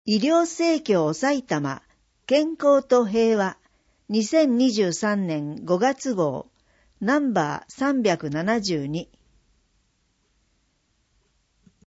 2023年5月号（デイジー録音版）